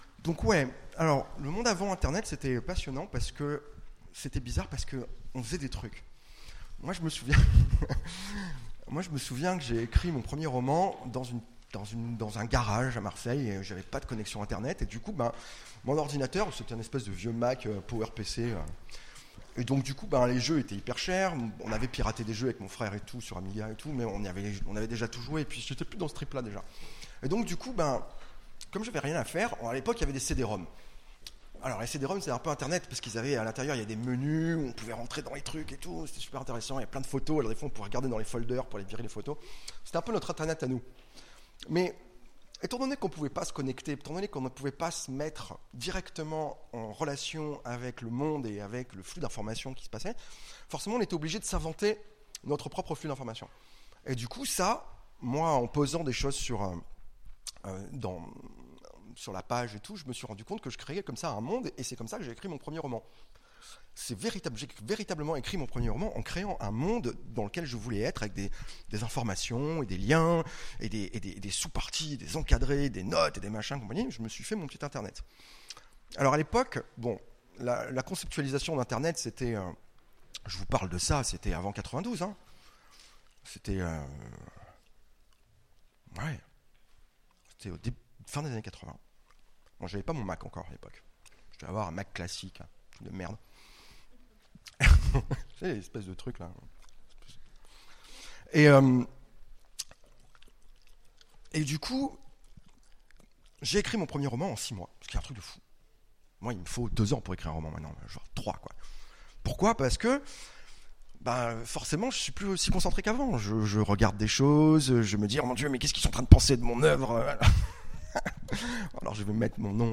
Utopiales 2017 : Conférence Demain sans internet